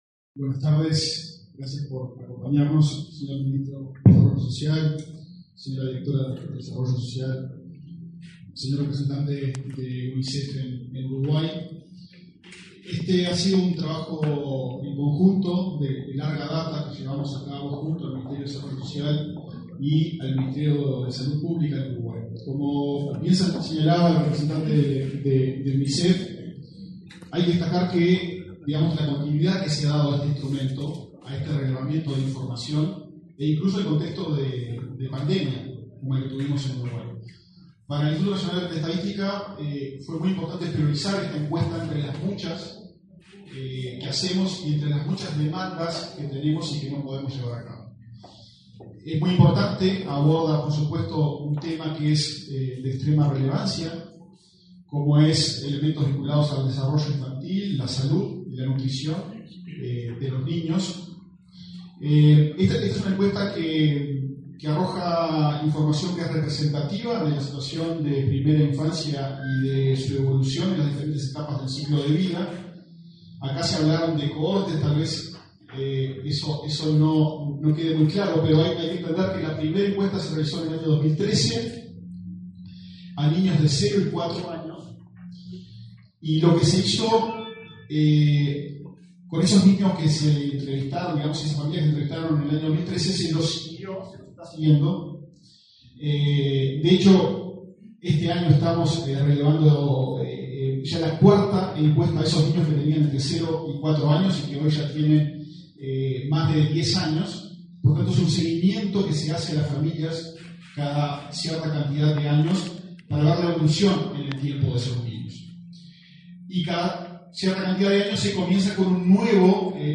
Palabras de autoridades en acto del MSP, Mides e INE
Palabras de autoridades en acto del MSP, Mides e INE 05/11/2024 Compartir Facebook X Copiar enlace WhatsApp LinkedIn El Ministerio de Salud Pública (MSP), el de Desarrollo Social (Mides) y el Instituto Nacional de Estadística (INE) presentaron, este martes 5 en Montevideo, los datos de la Encuesta de Nutrición, Desarrollo Infantil y Salud, cohorte 2023. En la oportunidad, se expresaron el director del INE, Diego Aboal; la titular del MSP, Karina Rando, y su par del Mides, Alejandro Sciarra.